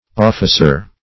Officer - definition of Officer - synonyms, pronunciation, spelling from Free Dictionary